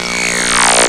TECHNO125BPM 2.wav